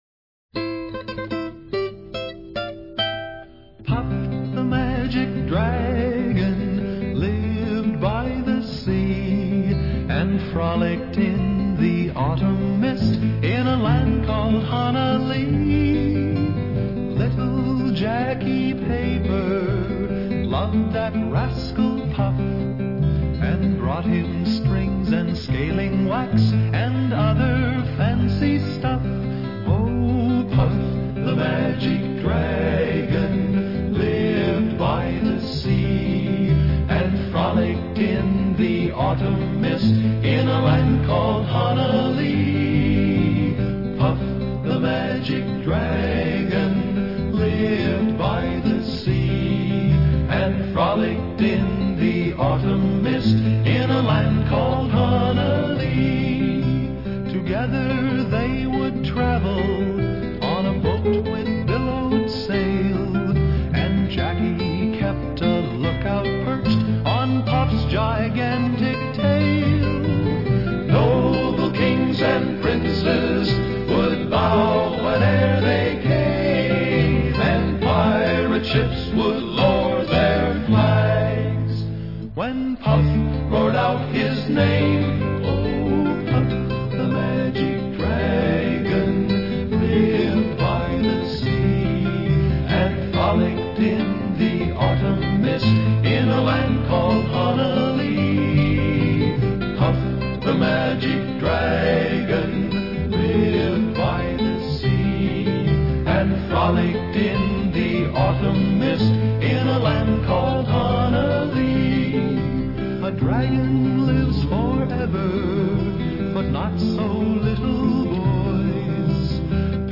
90年颁奖典礼上的英文medley